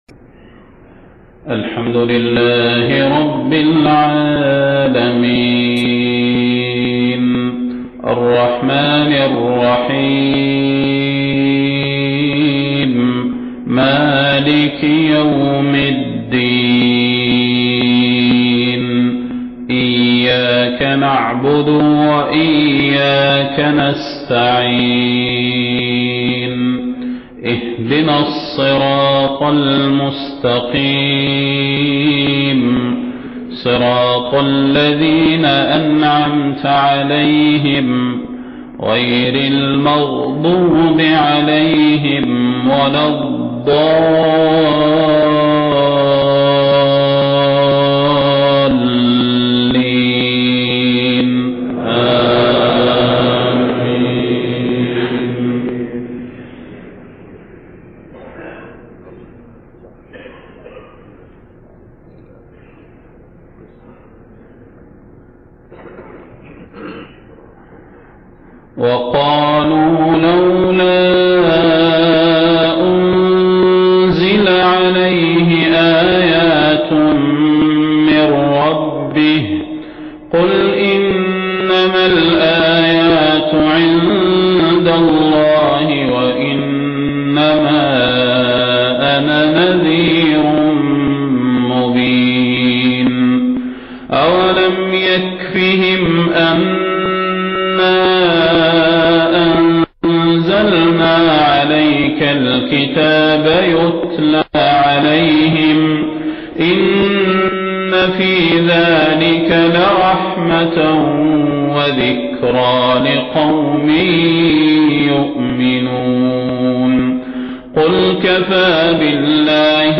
صلاة الفجر 5 ربيع الأول 1430هـ خواتيم سورة العنكبوت 50-69 > 1430 🕌 > الفروض - تلاوات الحرمين